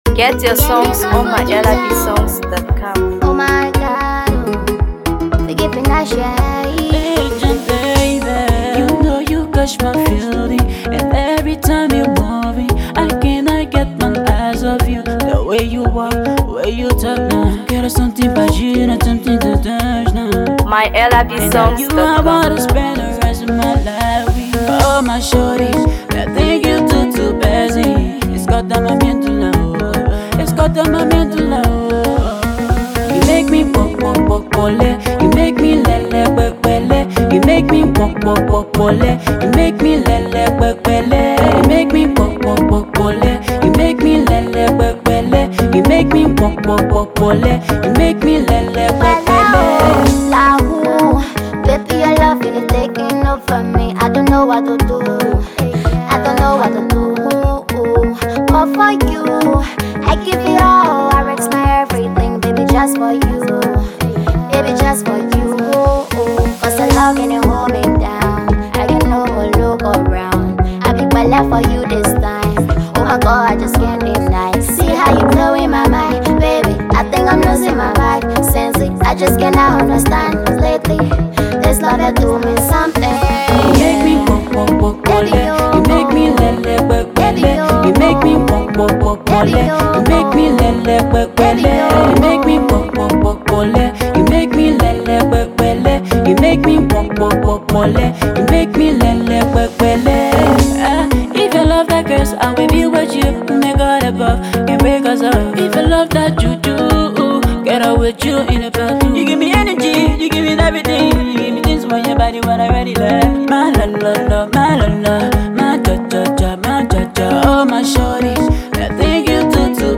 Afro Pop
Liberia singer-songwriter and music producer